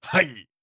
30. Effort Grunt (Male).wav